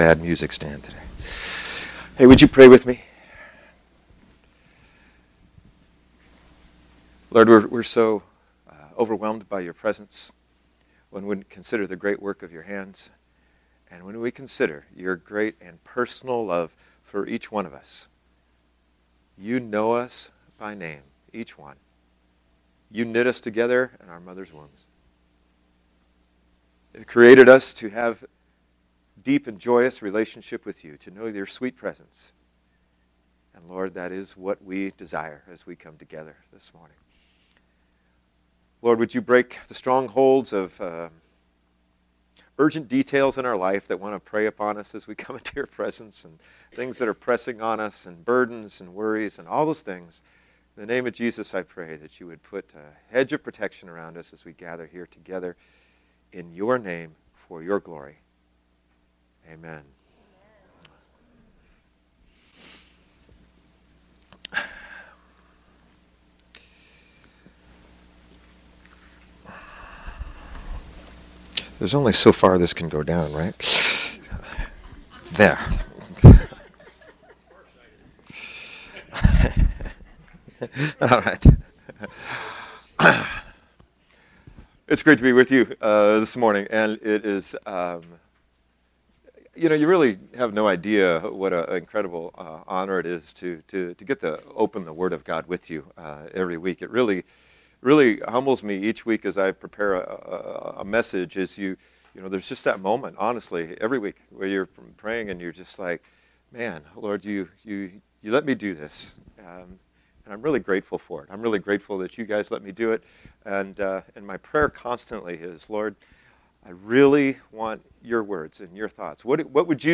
Sermons - Lighthouse Covenant Church